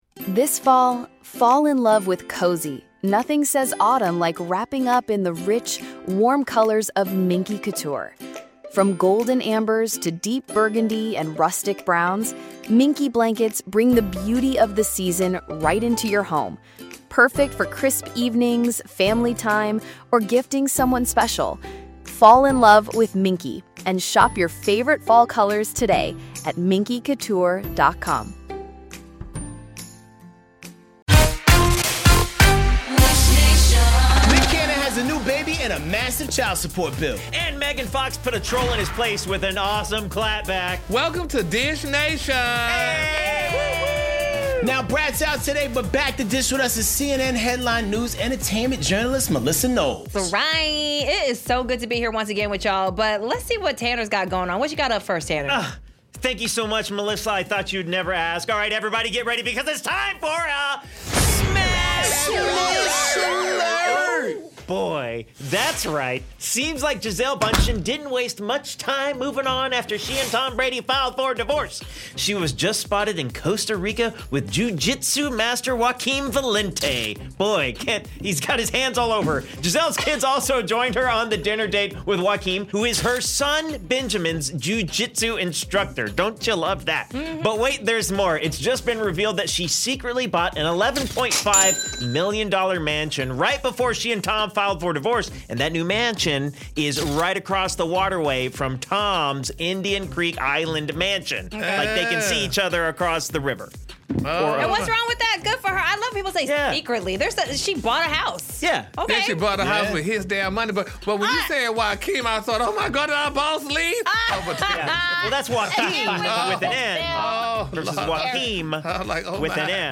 in studio so don't miss this Dish!